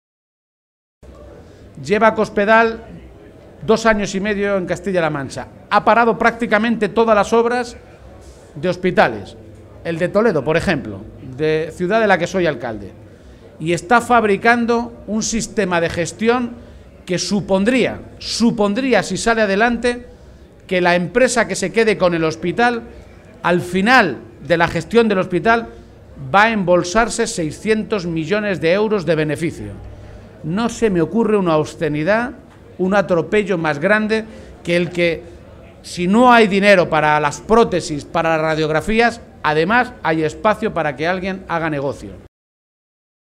En la atención a medios de comunicación, García Page advirtió que el PSOE de Castilla-La Mancha está dispuesto a seguir el camino marcado por los socialistas en Madrid «para impedir que algo que nos ha costado tanto construir como es la sanidad de la que nos sentimos tan orgullosos se la acaben apropiando unos pocos para hacer negocio».